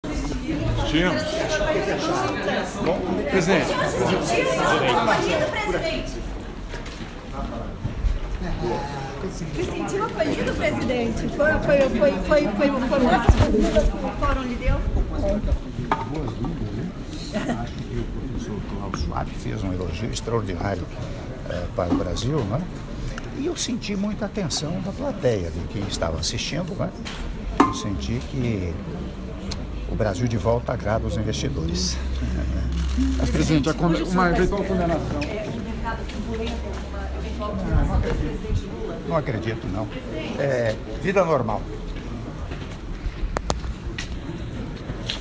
Áudio da Entrevista coletiva concedida pelo Presidente da República, Michel Temer, na chegada ao Hotel Seehof, após participação no Fórum Econômico Mundial 2018 - Davos/Suíça (45s)